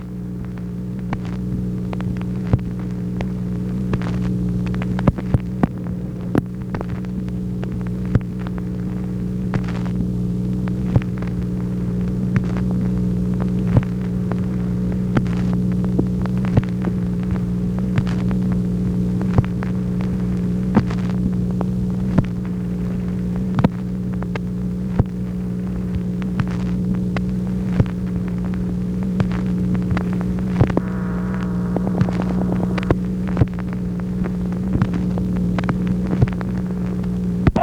MACHINE NOISE, January 22, 1964
Secret White House Tapes | Lyndon B. Johnson Presidency